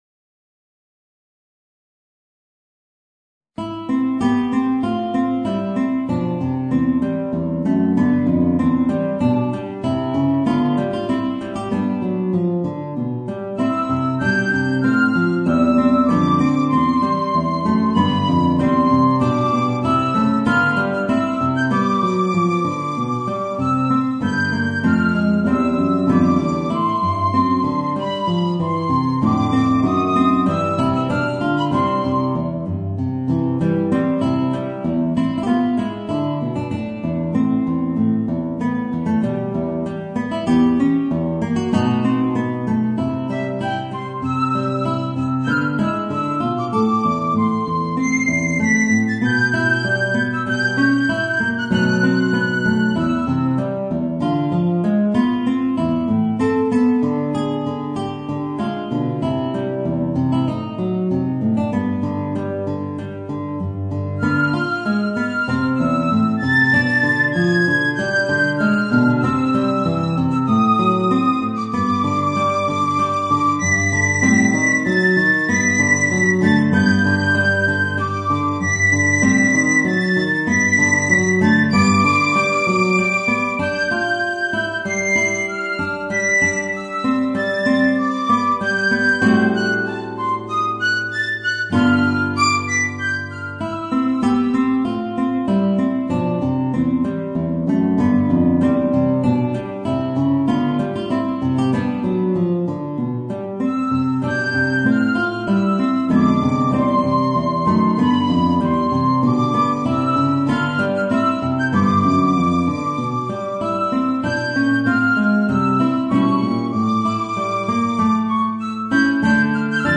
Voicing: Piccolo and Guitar